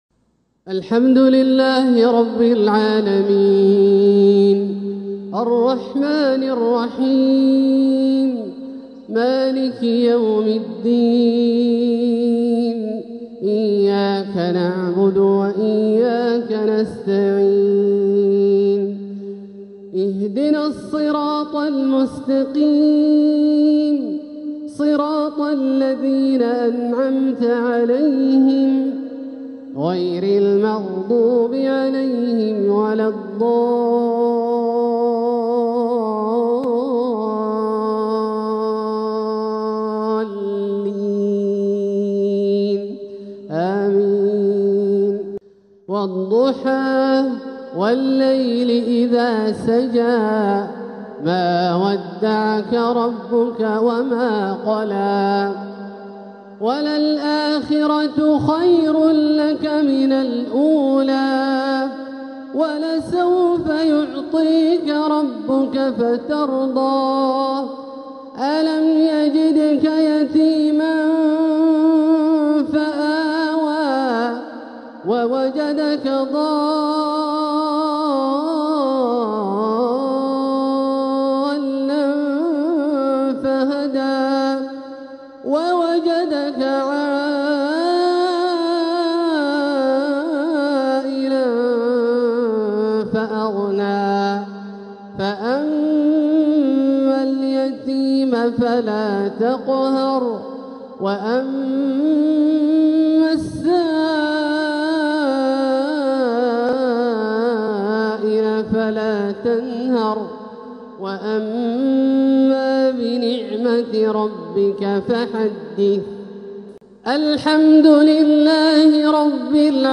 روائع العشائين | د. عبدالله الجهني يسطر أروع التلاوات الرائعة في نوبة العشائين من شهر صفر 1447هـ > سلسلة روائع العشائين > المزيد - تلاوات عبدالله الجهني